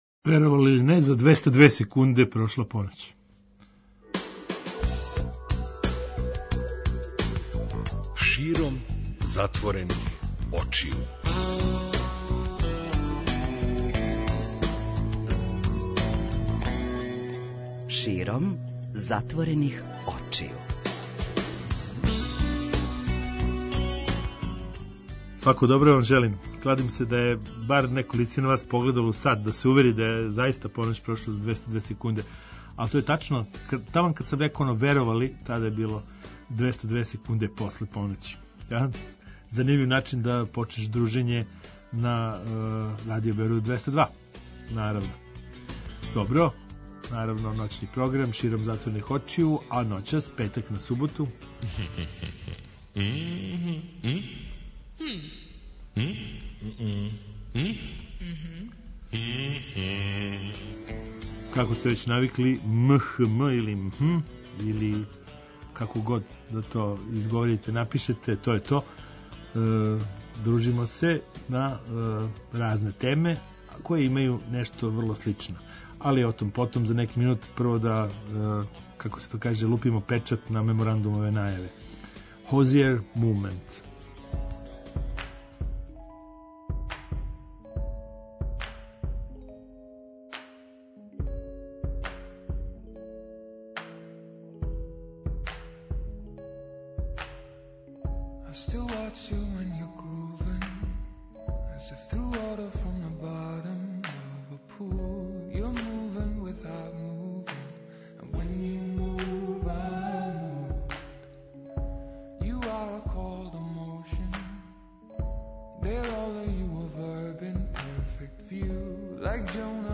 Зато ћемо и ноћас да причамо о неколико тема уз непрекидан контакт са публиком.